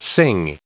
sing_en-us_recite_stardict.mp3